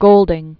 (gōldĭng), Sir William Gerald 1911-1993.